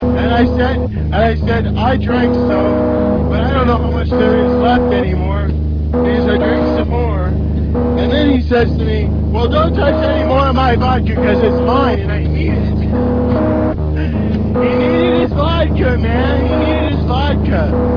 Guitar, Pitch Pipe, Sandal,
Yelled "CARP!" Occasionally